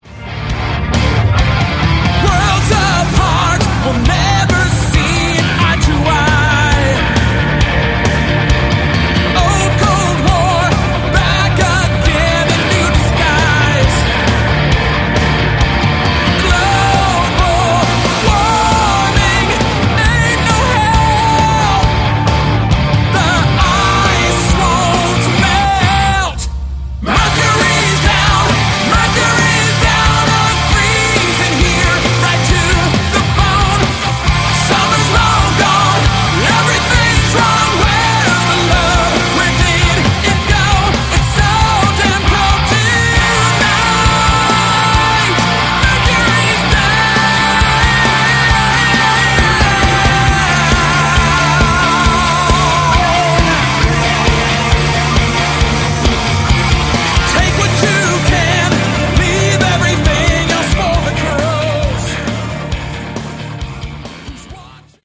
Category: AOR
lead vocals